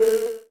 flyClose2.ogg